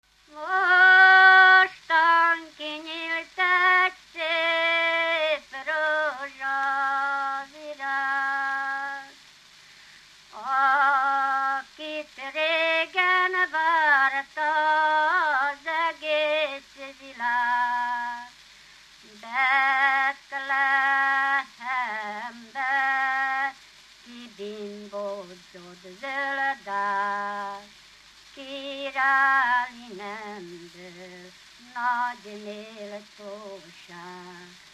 Alföld - Pest-Pilis-Solt-Kiskun vm. - Pereg
Műfaj: Népének
Stílus: 4. Sirató stílusú dallamok
Kadencia: 5 (4) 5 1